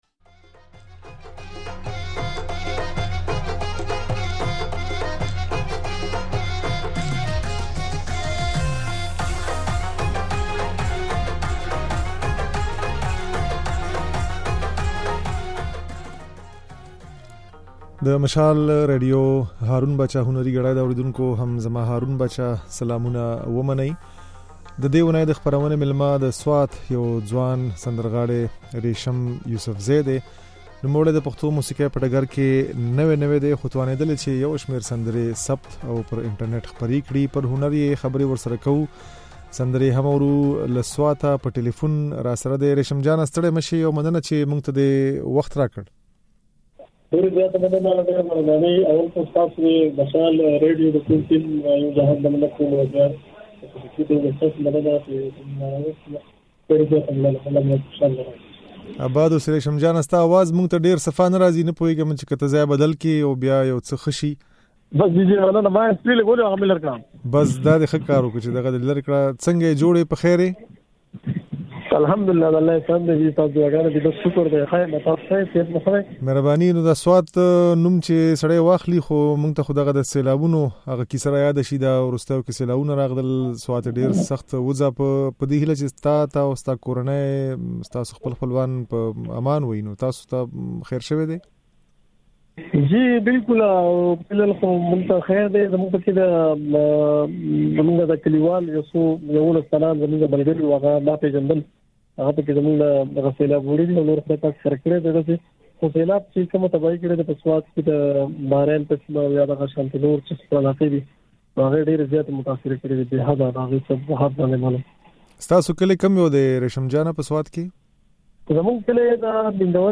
دا خبرې او ځينې سندرې يې د غږ په ځای کې اورېدای شئ.